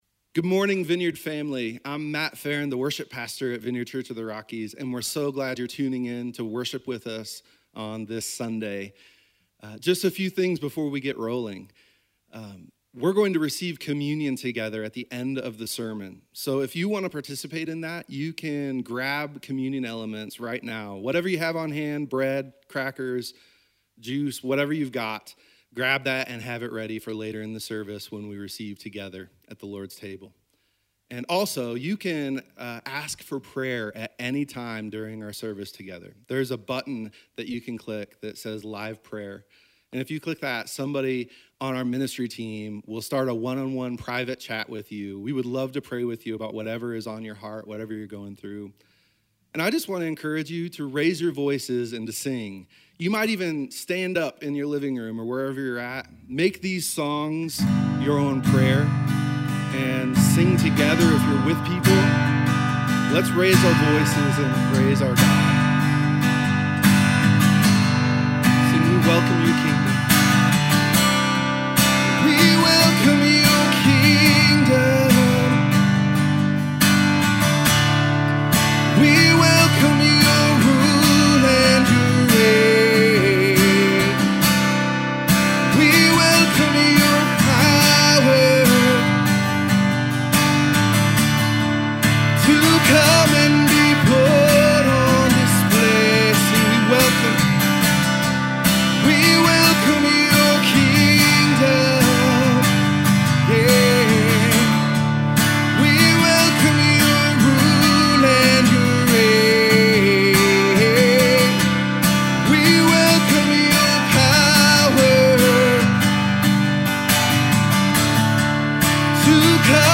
sermon-0503.mp3